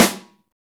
SNR XEXTS0JR.wav